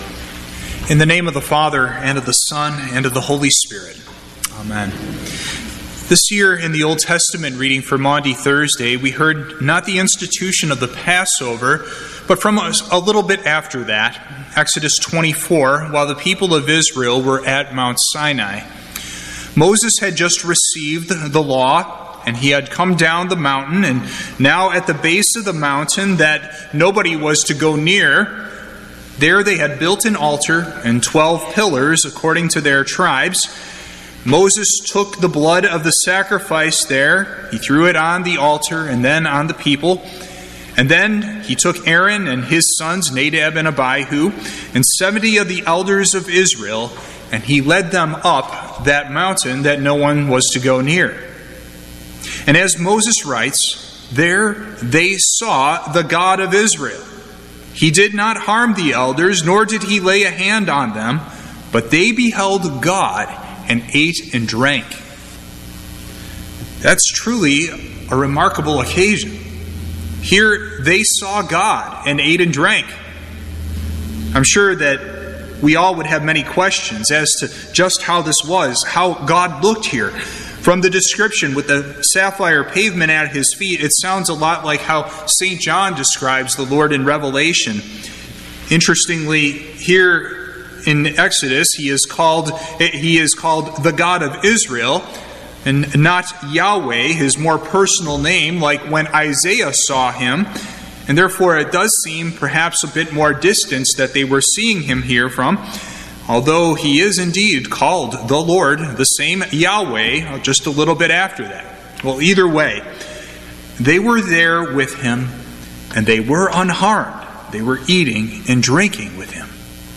Sermon
Holy Thursday